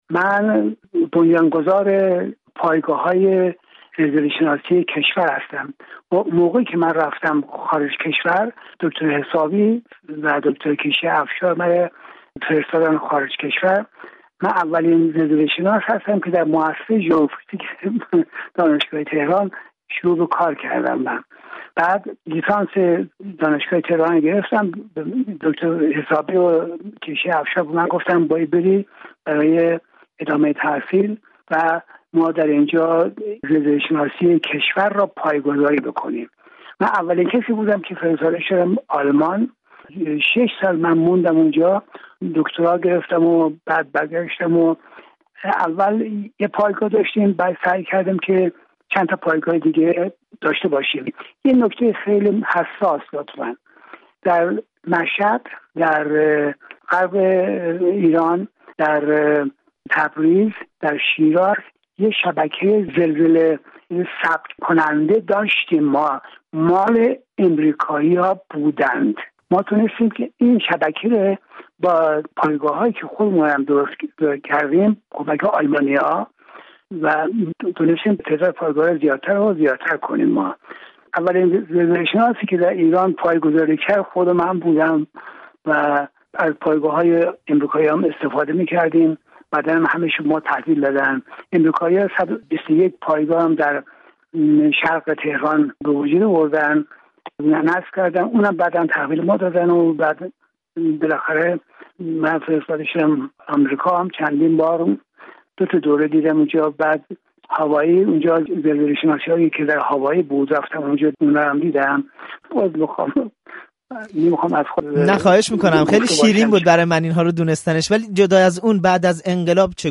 بهرام عکاشه، پدر دانش زلزله‌شناسی در ایران، در ۸۹ سالگی درگذشت. او در آخرین گفت‌وگویش با رادیو فردا می‌گوید؛ ایرانی‌ها به جای توکل به آسمان باید زلزله را پدیده‌ای زمینی تلقی کنند.